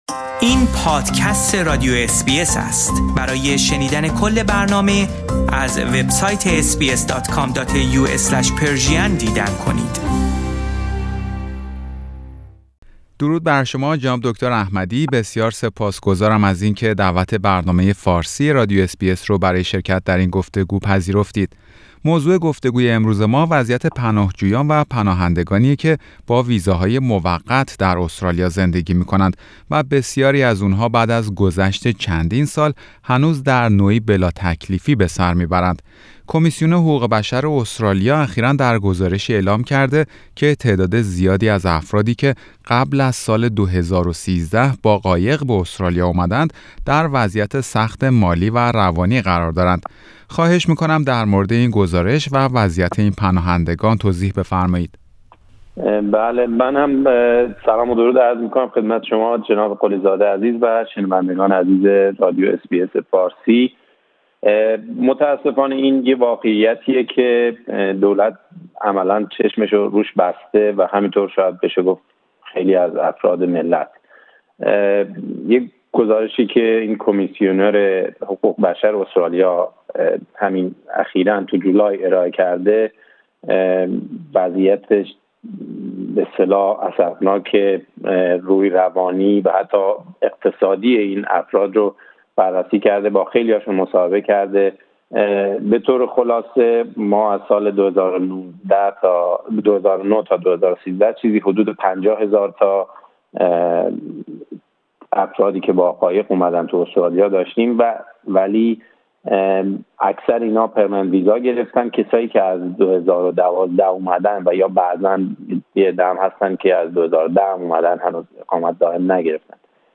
برنامه فارسی رادیو اس بی اس در مورد وضعیت این پناهندگان و شرایط اخذ مدرک سفر گفتگویی داشته